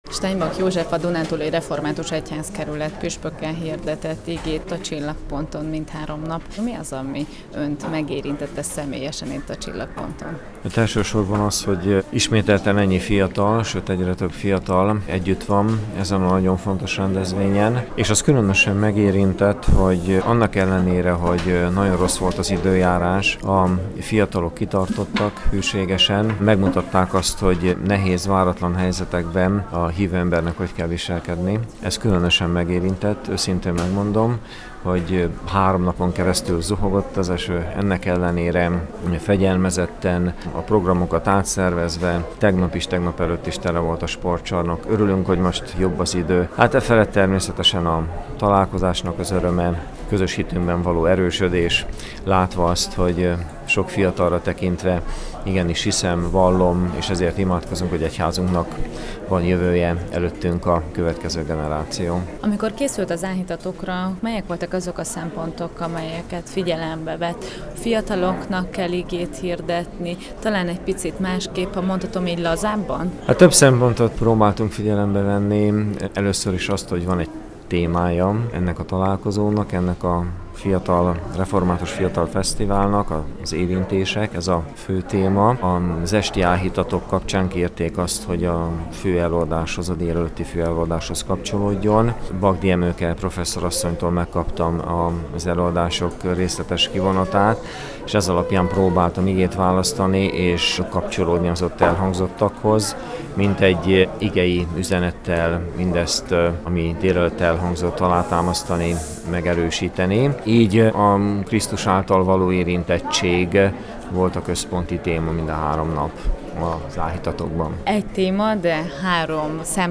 A Csillagpont mindhárom esti áhítatán a Dunántúli Református Egyházkerület püspökének igehirdetését hallgathatták meg a táborozók. Steinbach Józseffel beszélgettünk.